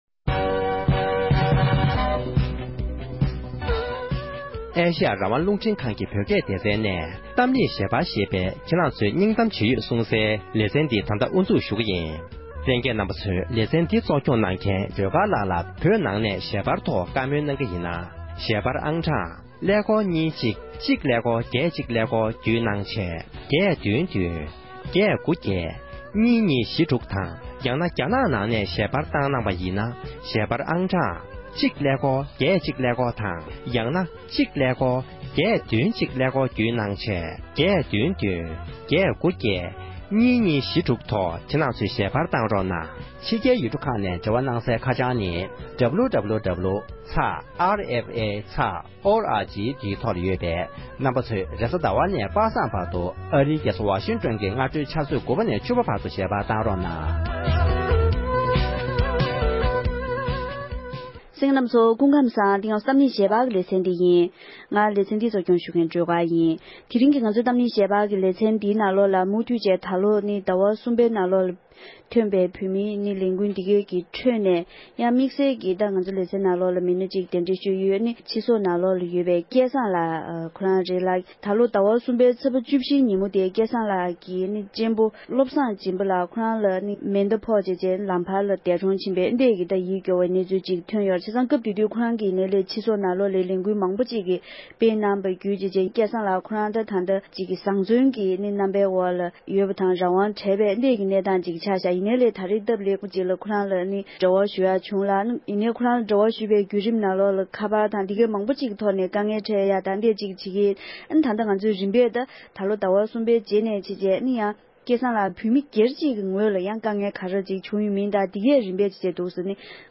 འདི་ལོའི་ཞི་བའི་ངོ་རྒོལ་སྐབས་རྒྱ་ནག་གི་དམག་མིའི་མཚོན་ཆའི་འོག་སྲོག་ཤོར་བའི་བོད་མི་འི་སྤུན་ཞིག་ལ་བཀའ་མོལ་ཞུས་པ།